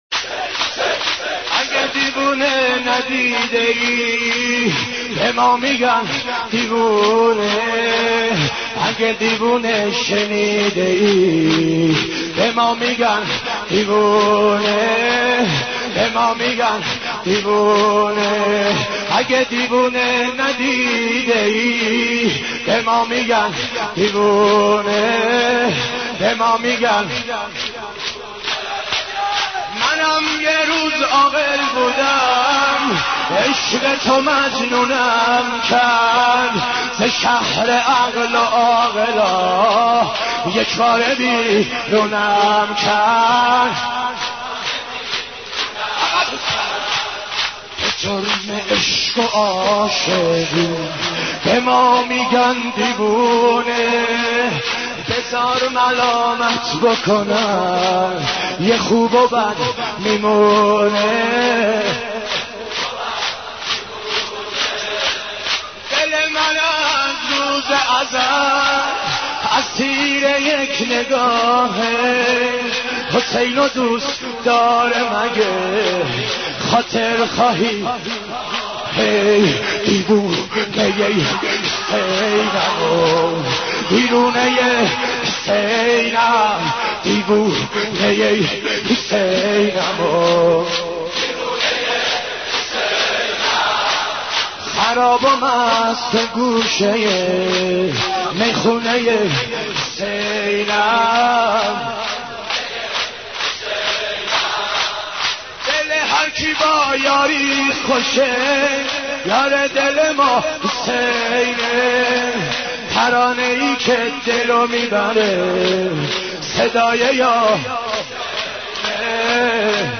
امام حسین ـ شور 39